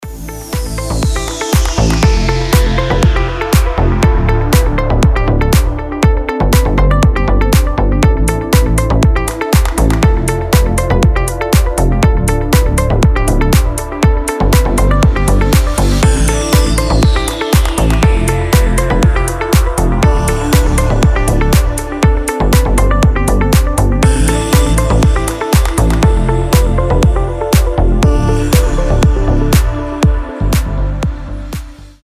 • Качество: 320, Stereo
deep house
nu disco
Indie Dance
Красивая deep мелодия